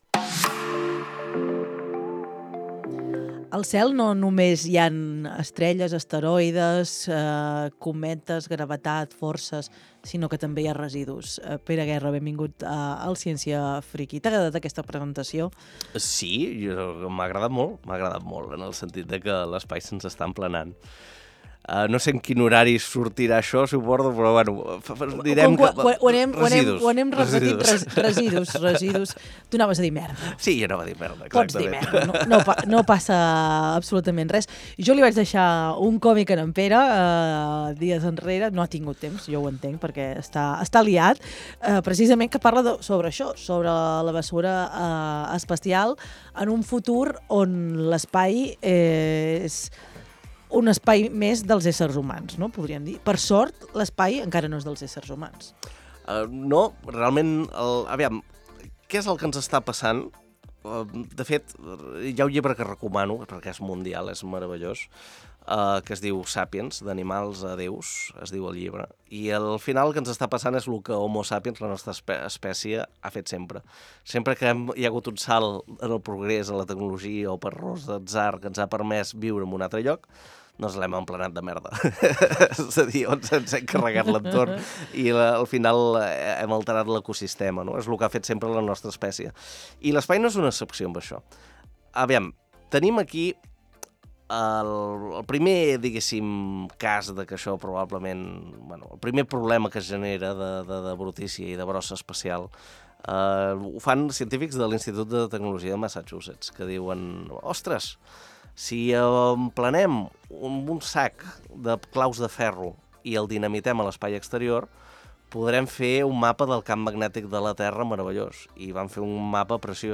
Una conversa plena de dades curioses, frikisme científic i humor!🎧 Escolta l’episodi